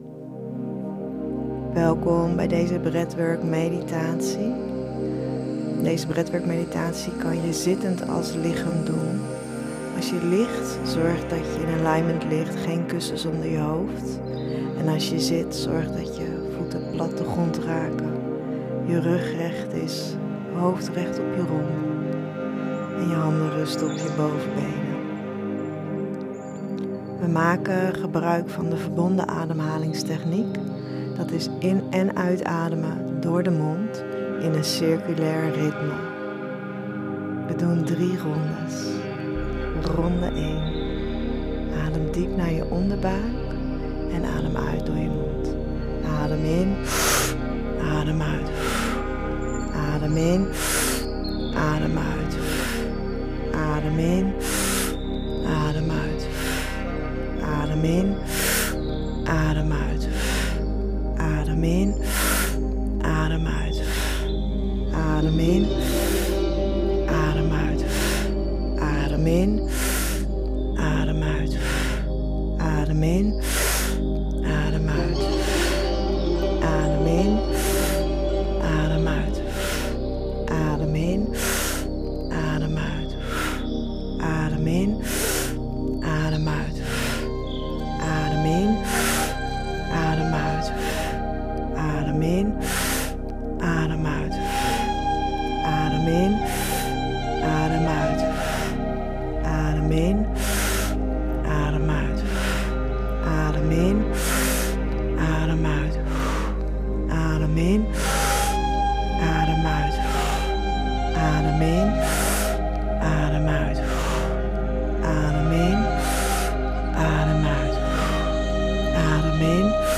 Breathwork meditatie